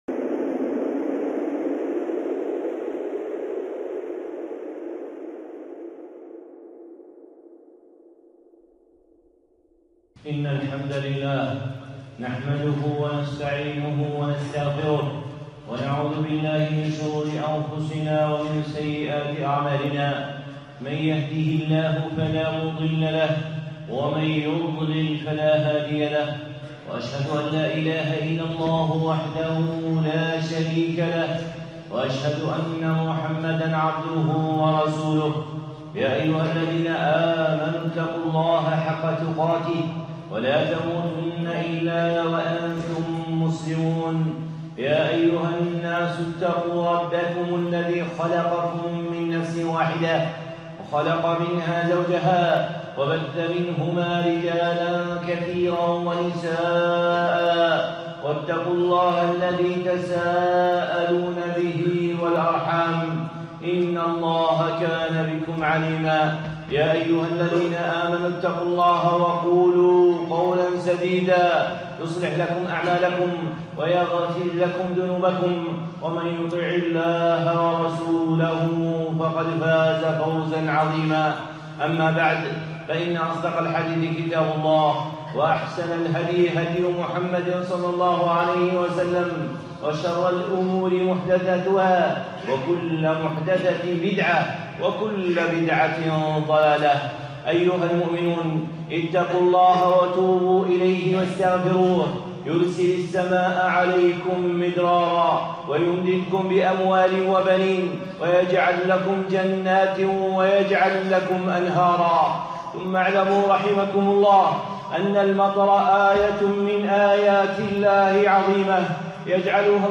خطبة (العارض المُمْطُر)